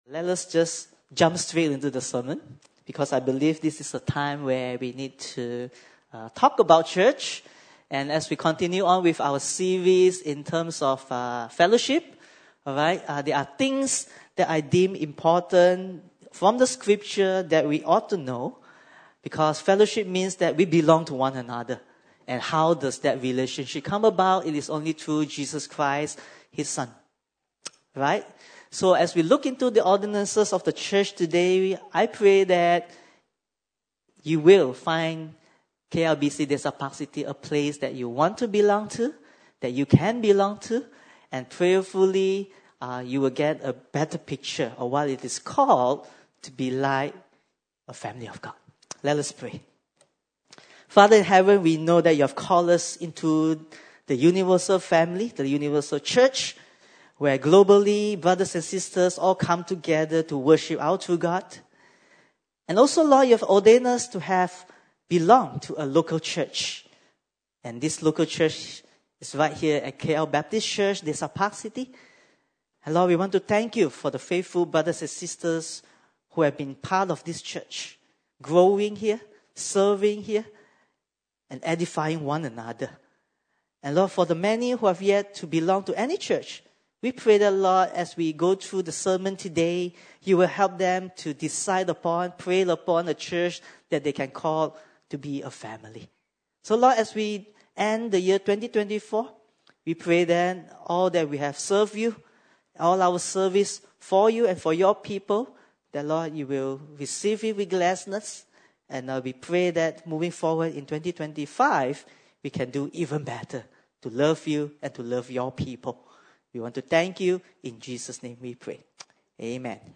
1 Corinthians 11:23-29 Service Type: Sunday Service